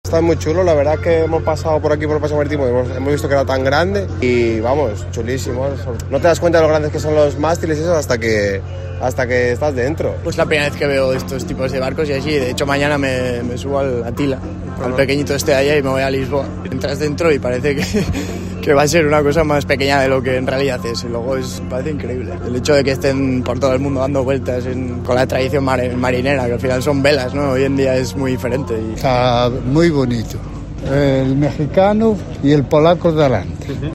Asistentes a la escala de la Tall Ships Races en A Coruña